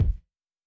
Kick Hot Wind Blows.wav